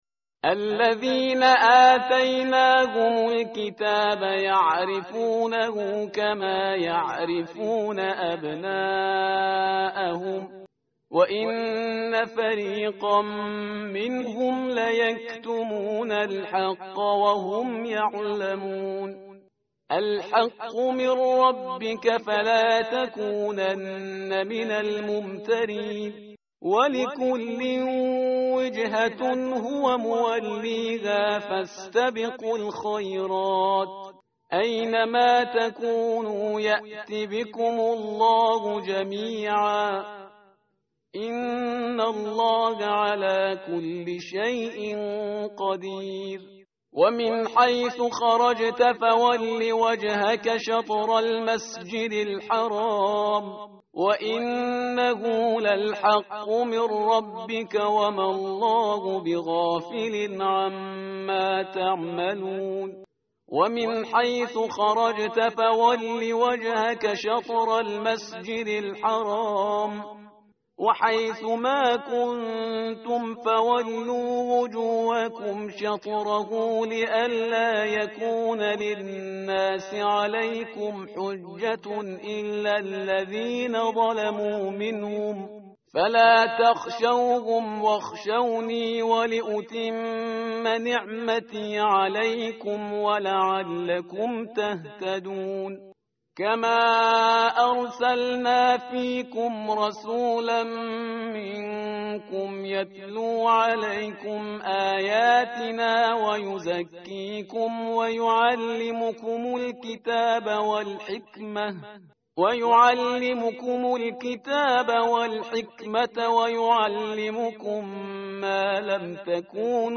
متن قرآن همراه باتلاوت قرآن و ترجمه
tartil_parhizgar_page_023.mp3